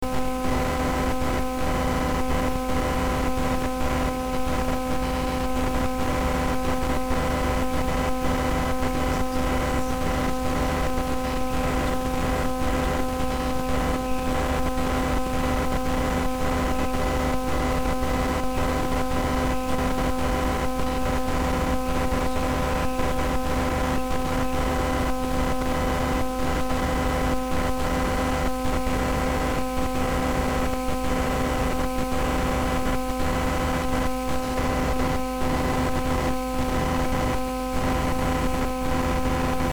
Â Set up induction loop close to extension socket with microphone. Â Talk into the microphone to see if the ground of power supply will transmit your words into the recording computer through electromagnetic induction.
26 aug computer to ground power supply record via em induction jack and jill
26-aug-computer-to-ground-power-supply-record-via-em-induction-jack-and-jill.mp3